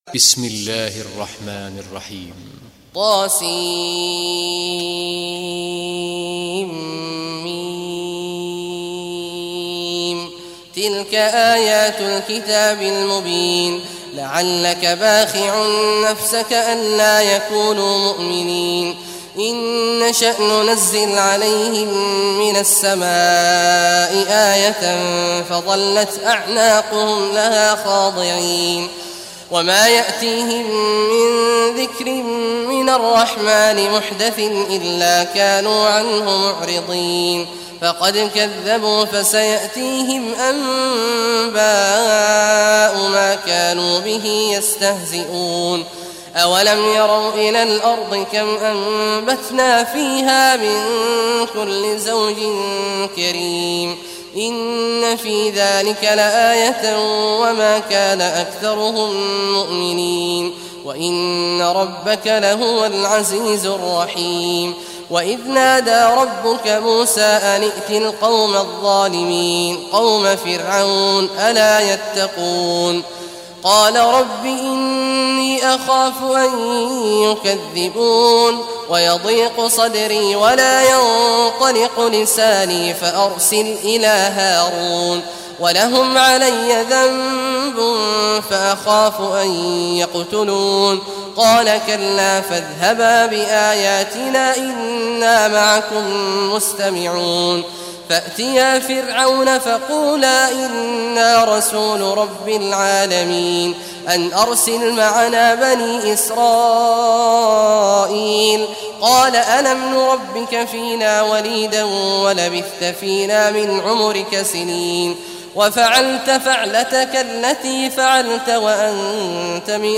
Surah Ash Shuara Recitation by Sheikh Juhany
Surah Ash Shuara, listen or play online mp3 tilawat / recitation in Arabic in the beautiful voice of Sheikh Abdullah Awad al Juhany.